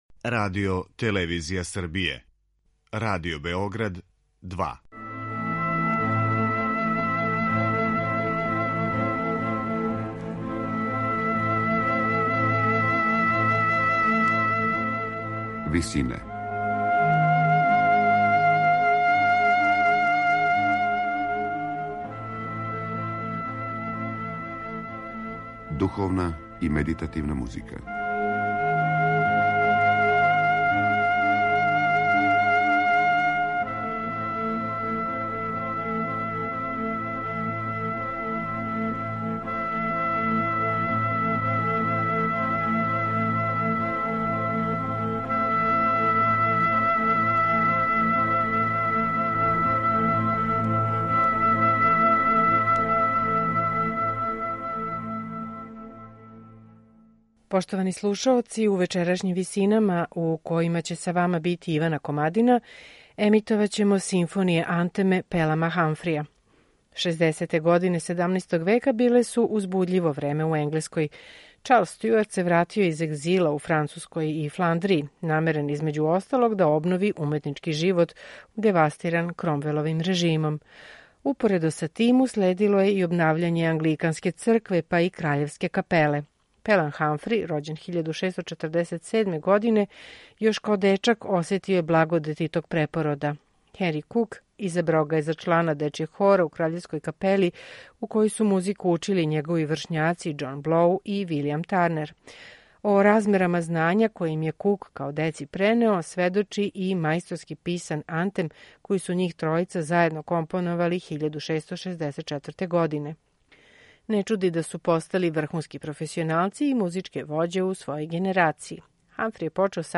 Пелам Хамфри: Симфоније-антеми